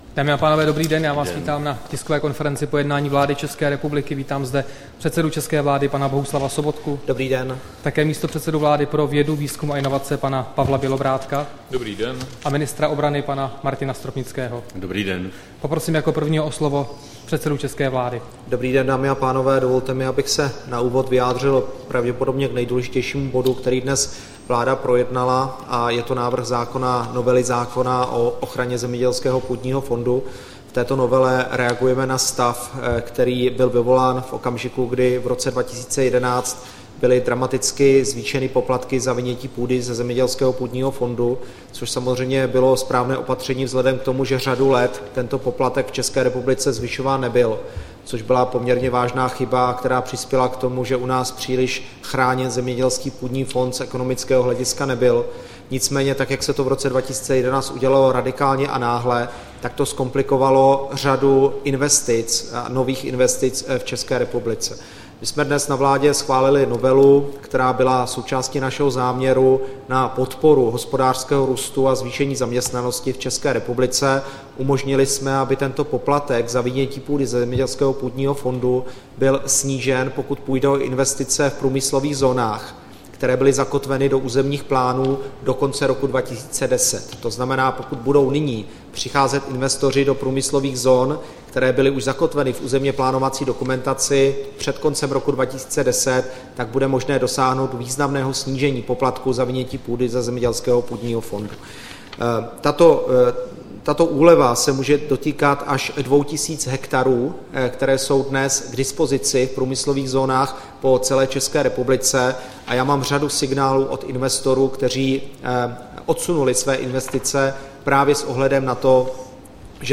Tisková konference po jednání vlády, 8. října 2014